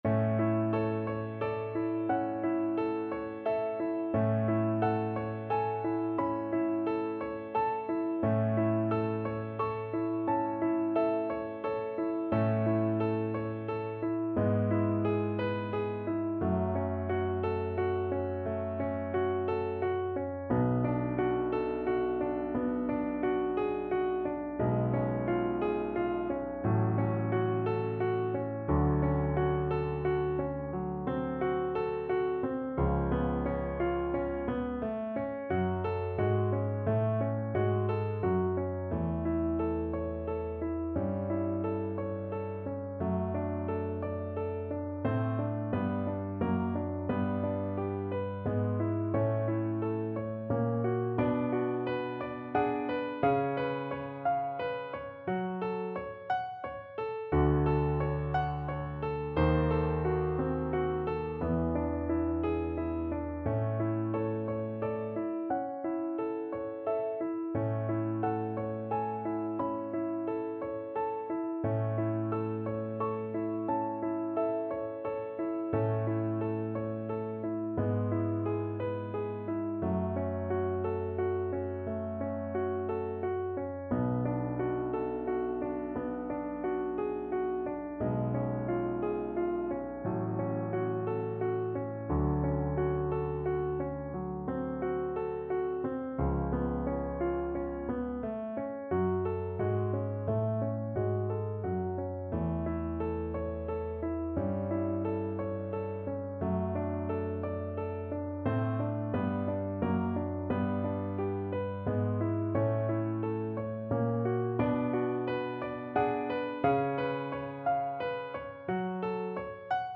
6/4 (View more 6/4 Music)
~ = 88 Andante
Classical (View more Classical Viola Music)